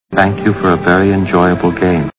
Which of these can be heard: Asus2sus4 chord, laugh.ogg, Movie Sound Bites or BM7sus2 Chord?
Movie Sound Bites